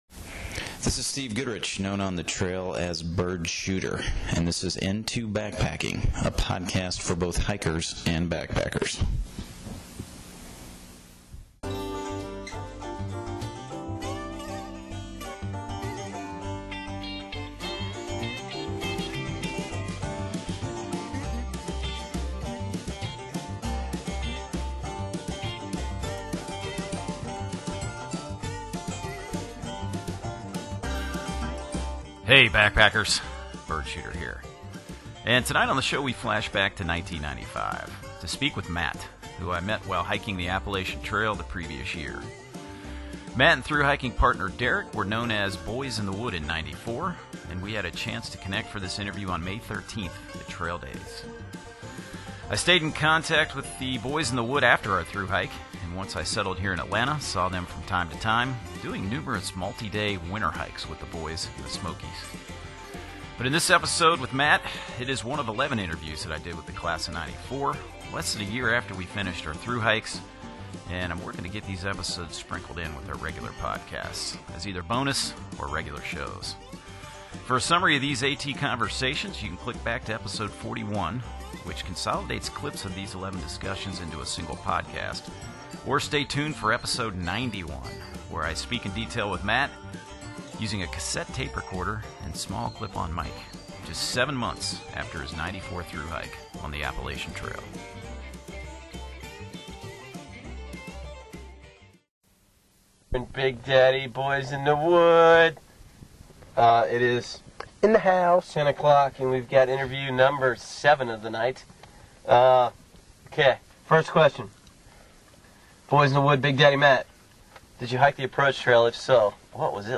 This is the fifth release in a special series to celebrate the 25th anniversary of our hike, and it was recorded at Trail Days on May 13, 1995 in Damascus, Virginia.
The interview was done in a car and with a small handheld mic and cassette tape recorder. So don’t expect high fidelity in this recording – but I’m grateful to have this and a total of 11 recordings of my thru-hiking buddies from 1994.